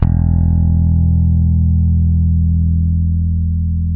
-JP ROCK.E.2.wav